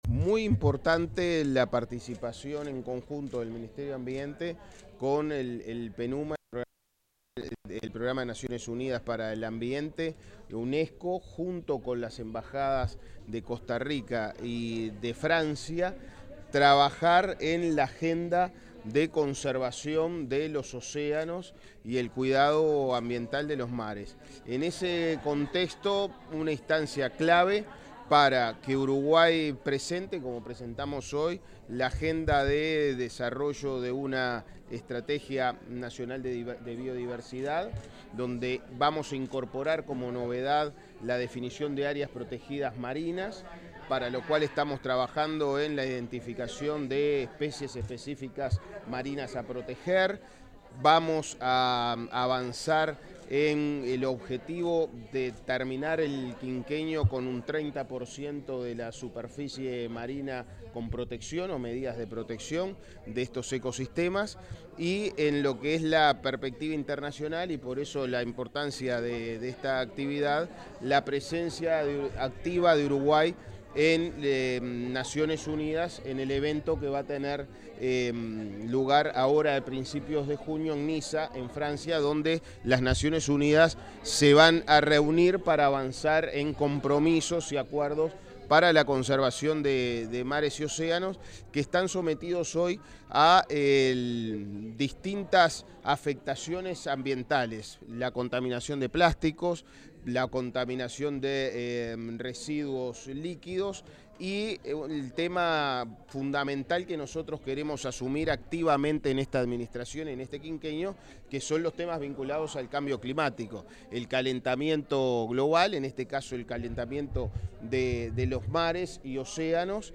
Tras la segunda edición del Blue Talk Montevideo, el ministro de Ambiente, Edgardo Ortuño, realizó declaraciones a la prensa.